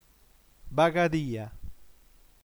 bagadiu ag nm ba.ga.dì.u (f. -a) - [baɣa'ðiu] (f. [-a]) ◊